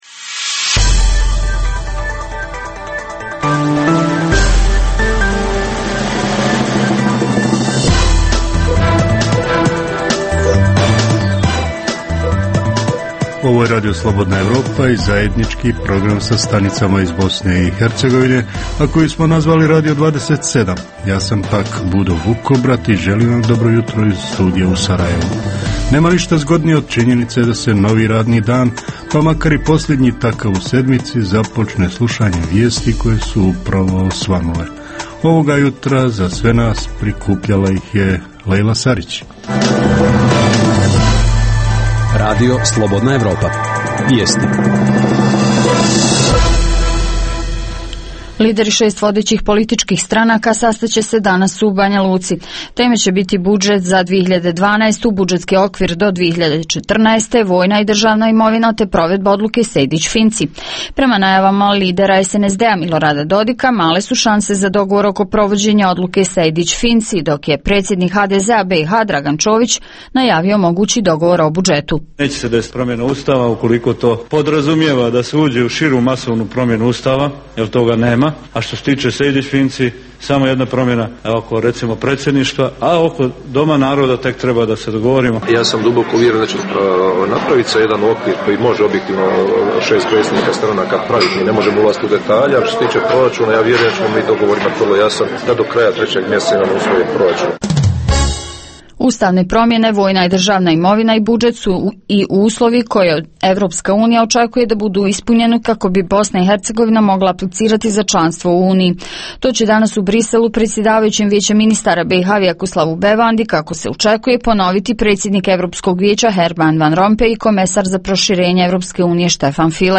Jutarnji program za BiH koji se emituje uživo. Sadrži informacije, teme i analize o dešavanjima u BiH i regionu. Reporteri iz cijele BiH javljaju o najaktuelnijim događajima u njihovim sredinama.
Redovni sadržaji jutarnjeg programa za BiH su i vijesti i muzika.